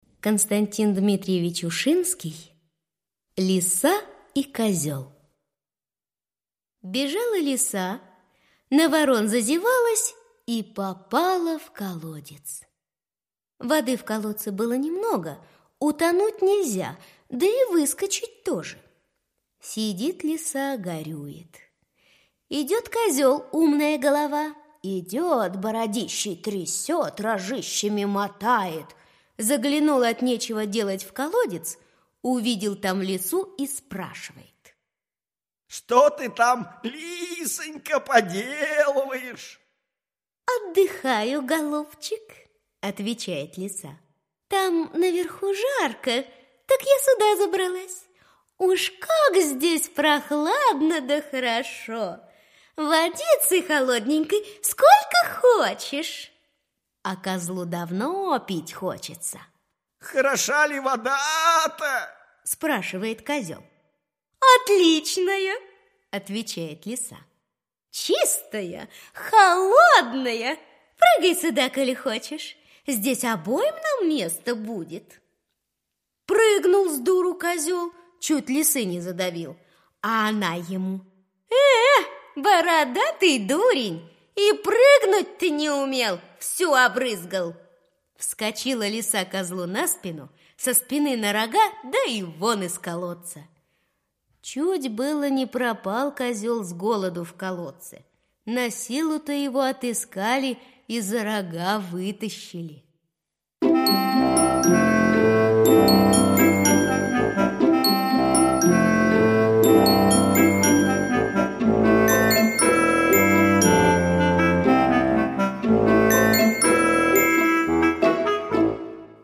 На данной странице вы можете слушать онлайн бесплатно и скачать аудиокнигу "Лиса и козел" писателя Константин Ушинский.